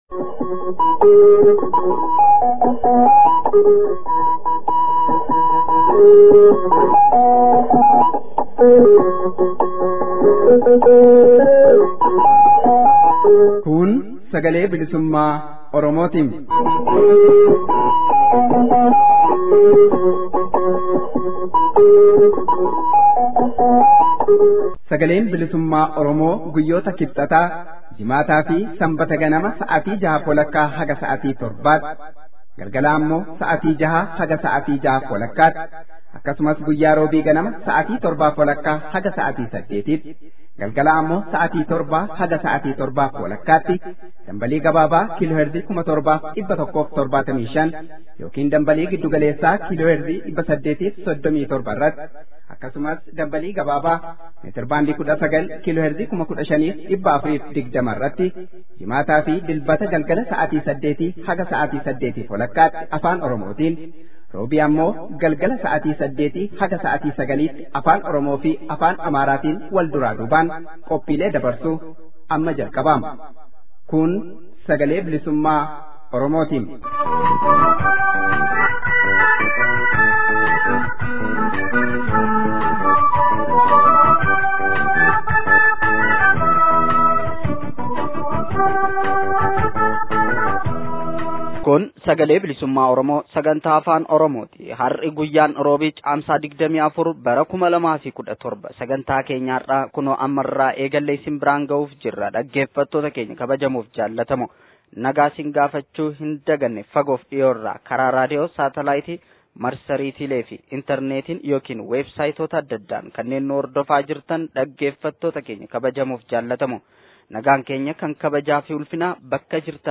Oduu